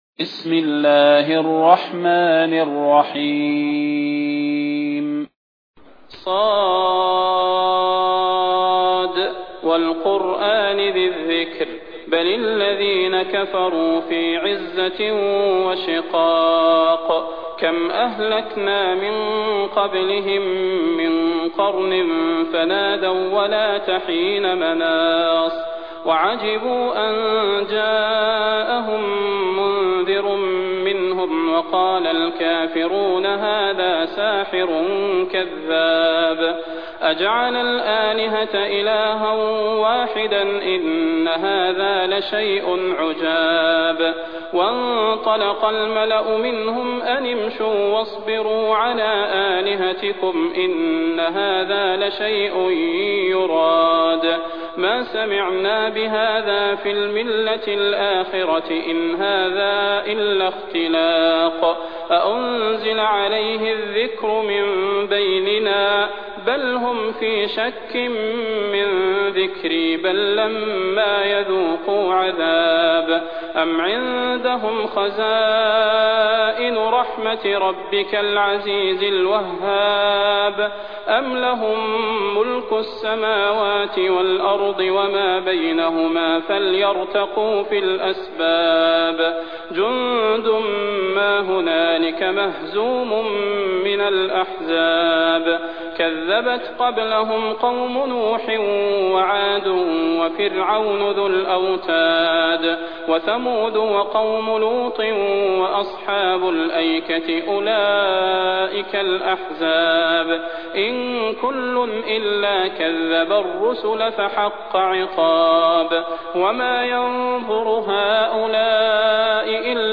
المكان: المسجد النبوي الشيخ: فضيلة الشيخ د. صلاح بن محمد البدير فضيلة الشيخ د. صلاح بن محمد البدير ص